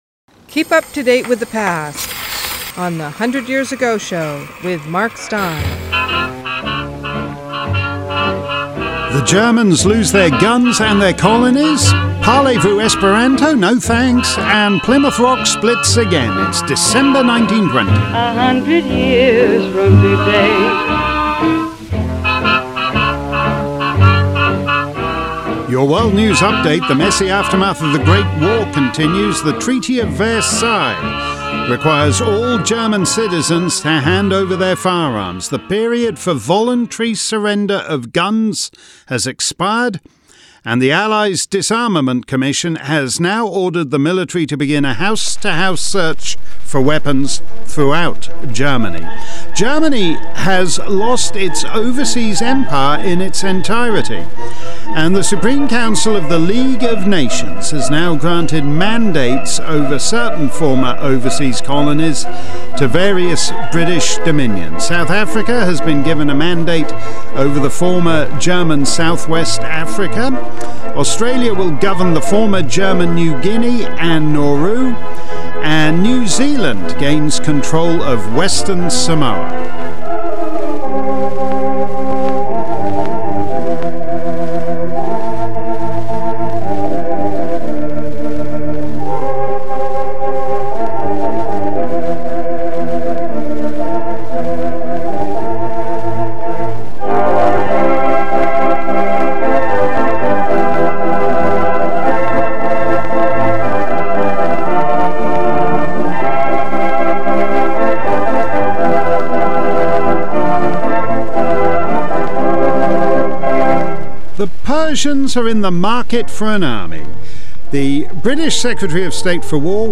Plus all the other headlines from Esperanto to Everest - and the sounds of the era from Al Jolson to Gene Lockhart.